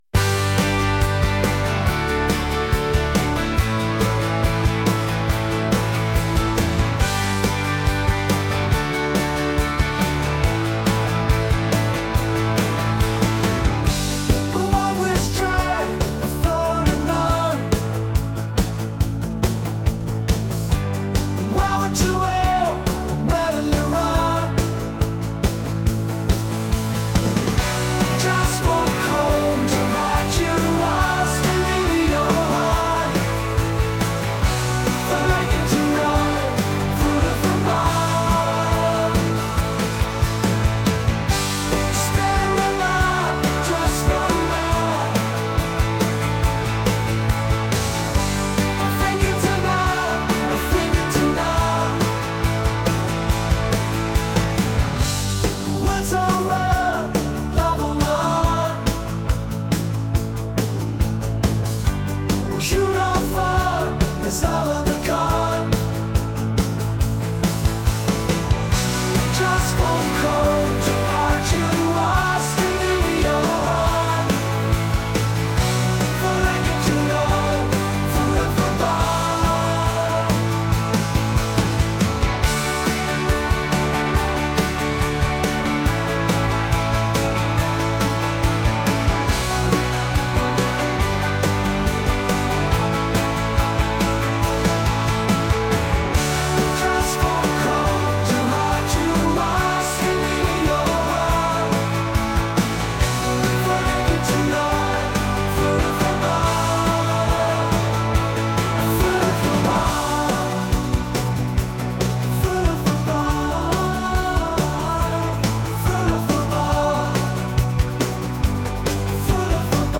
pop | cinematic | indie